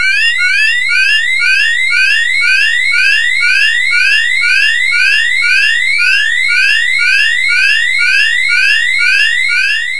■画面通知アクションのブザー音について
画面通知アクションのブザー音を確認することができます。
caution.wav